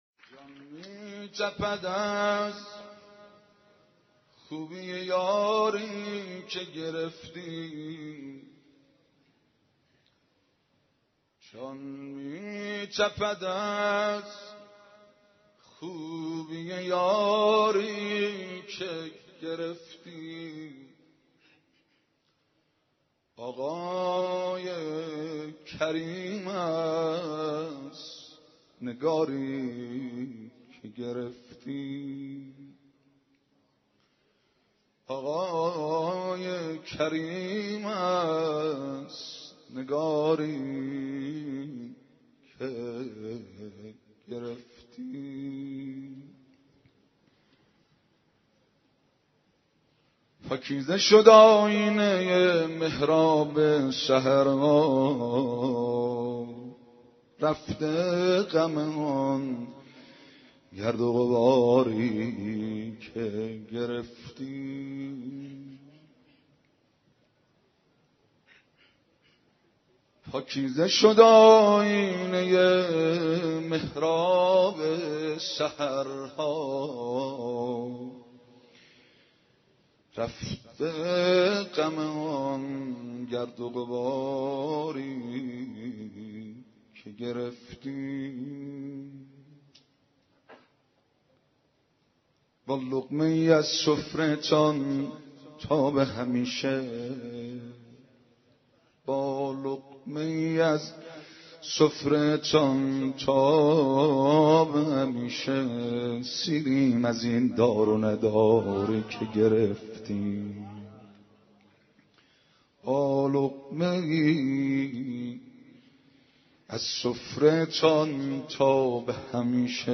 ولادت امام حسن (ع) - مدح - جان می تپد از خوبی یاری که گرفتی